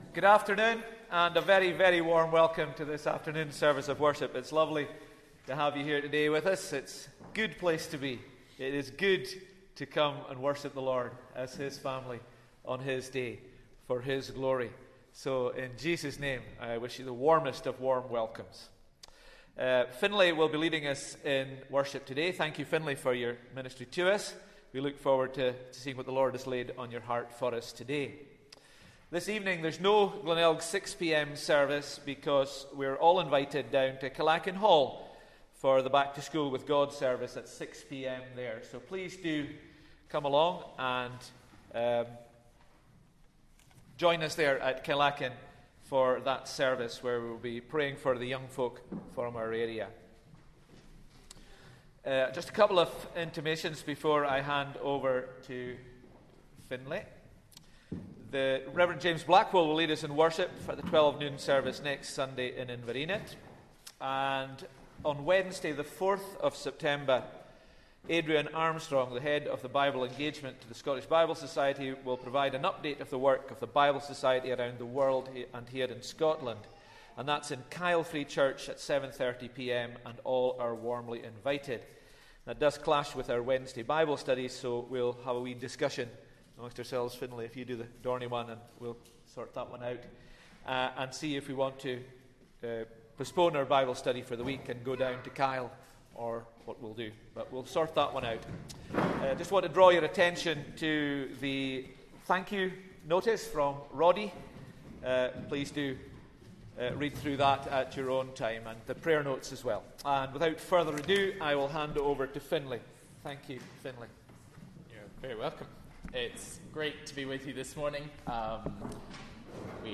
Sunday Service 1st September 2024